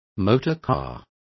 Complete with pronunciation of the translation of motorcars.